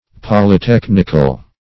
Polytechnical \Pol`y*tech"nic*al\, a.